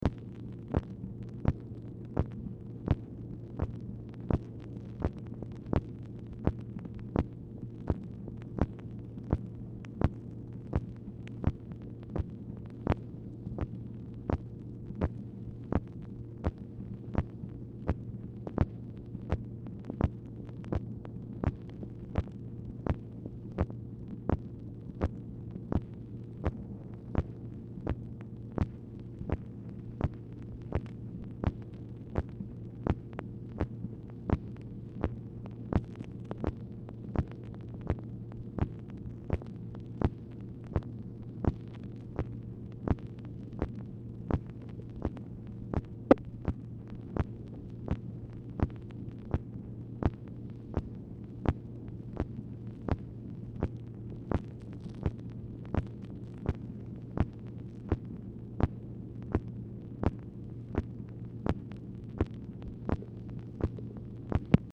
Telephone conversation # 10150, sound recording, MACHINE NOISE, 5/31/1966, time unknown | Discover LBJ
Format Dictation belt